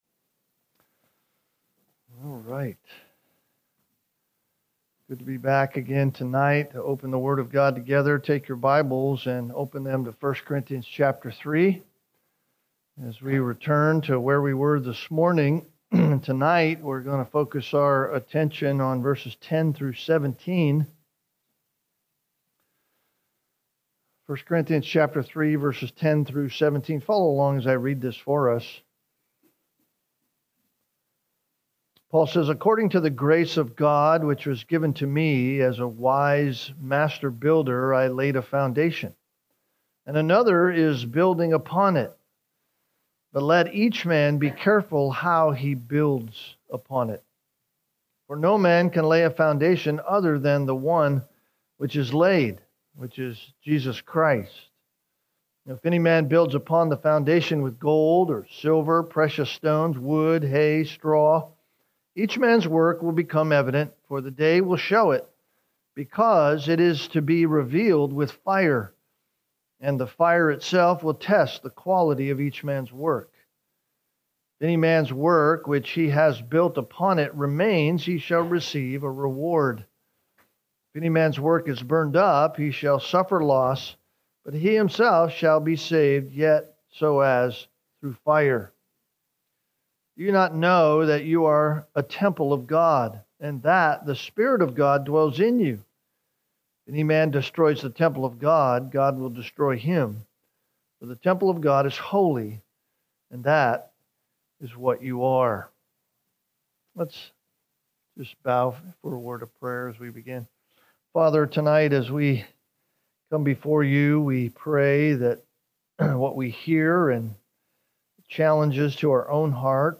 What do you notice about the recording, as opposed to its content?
Sunday Evening - Fellowship Bible Church